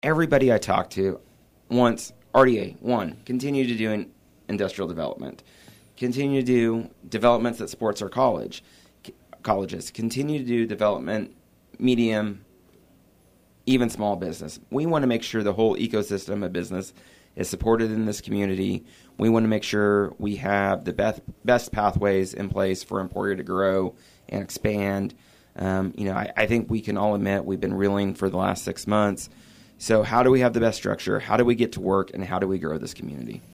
Q&A With Trey is a recurring segment with Emporia City Manager Trey Cocking on KVOE's Talk of Emporia every third Monday.